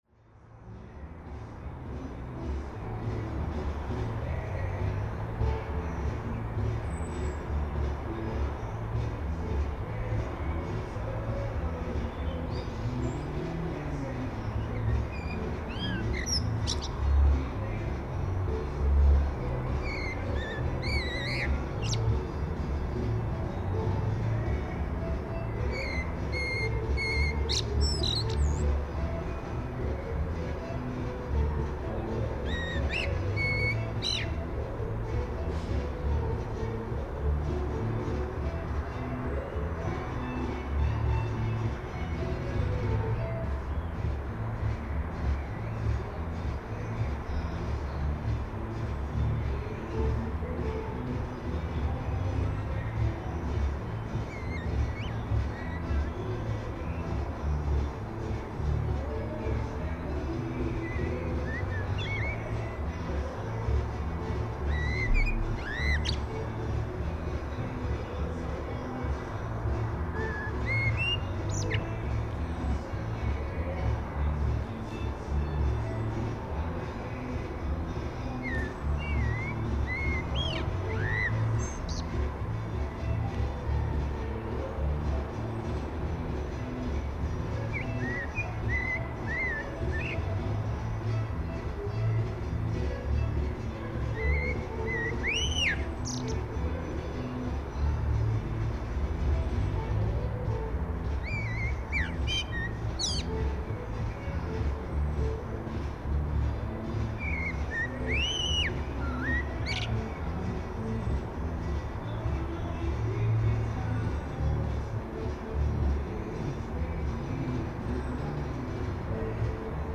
It was recorded in the evening 9th of June 2012 in my garden.
t220-blackbird-song.mp3